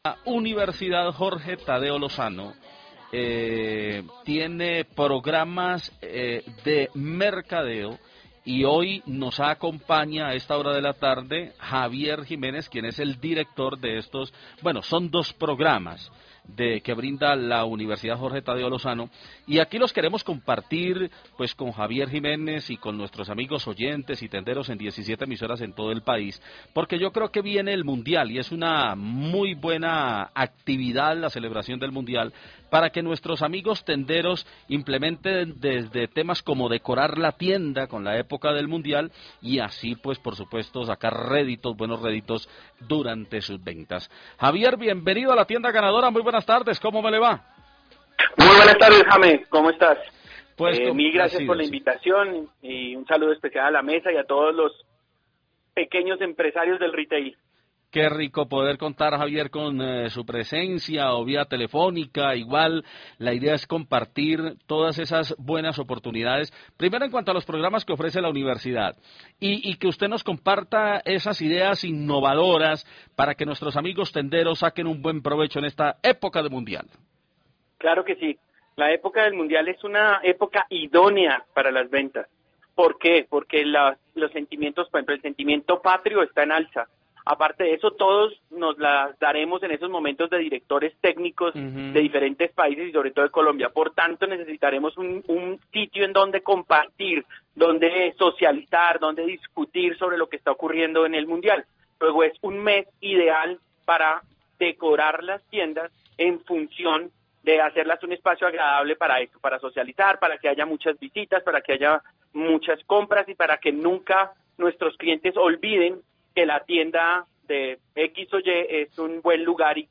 Conozca la entrevista completa en RCN Radio .